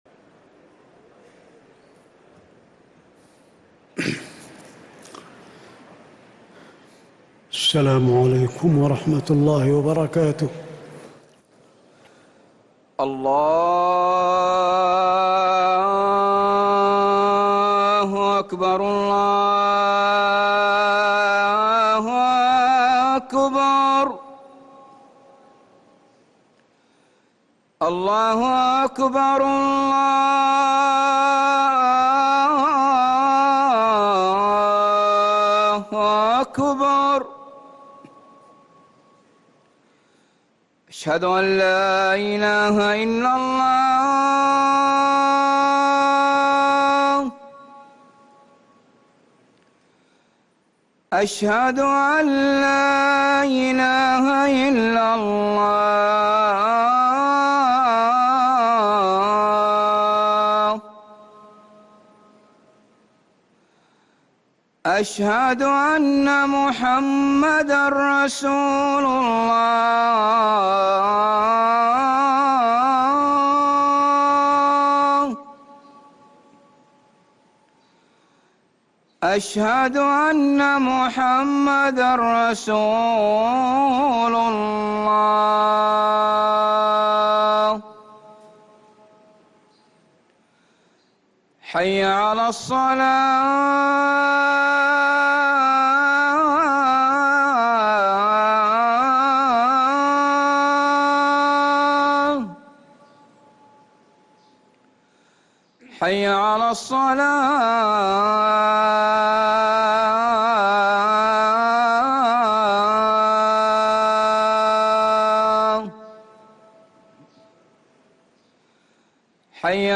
أذان الجمعة الثاني للمؤذن سعود بخاري 10 ربيع الأول 1446هـ > ١٤٤٦ 🕌 > ركن الأذان 🕌 > المزيد - تلاوات الحرمين